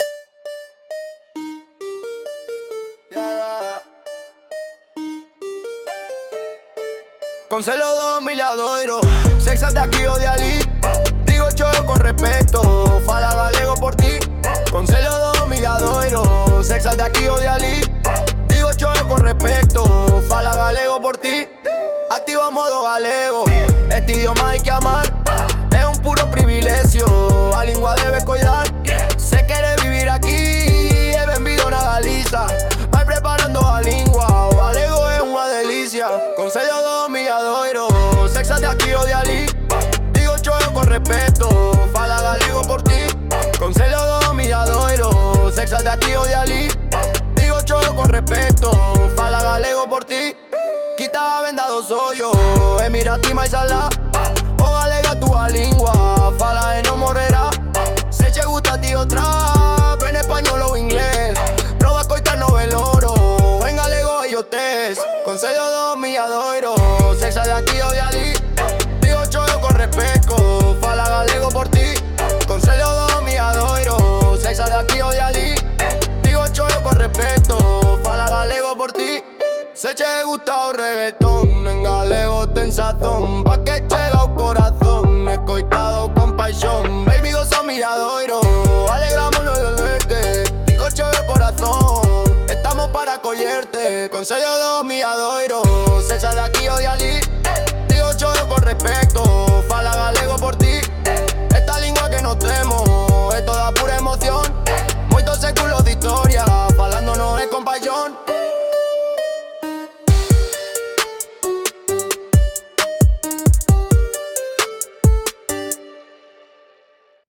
Queremos presentarvos algo moi chulo que fixo o alumnado de música de 4º de ESO do IES do Milladoiro.
Mandaron dúas versións da canción.
Versión trap:
Trap.m4a